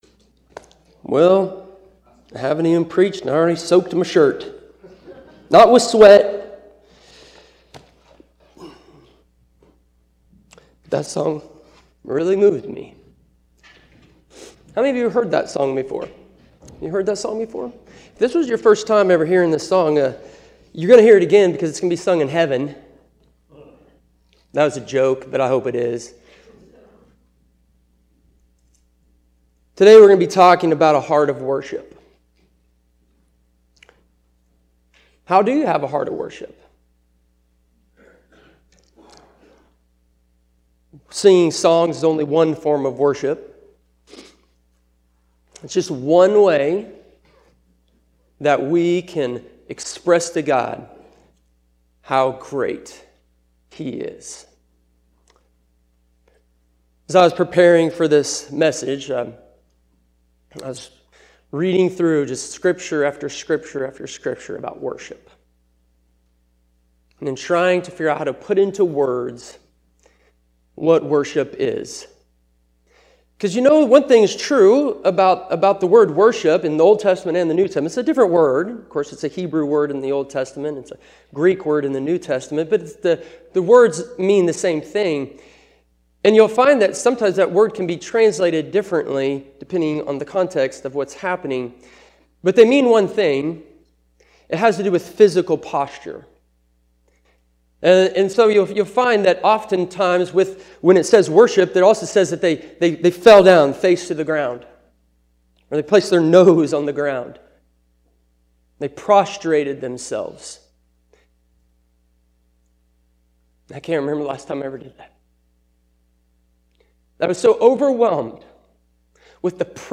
Sermon Summary You and I were made for worship, but cultivating our hearts to give it to the one true God is another matter entirely.